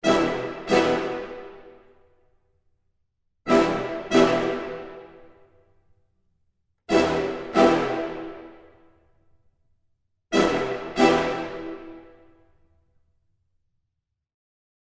After that I will load the brass samples…
OrchestralHits_Brass.mp3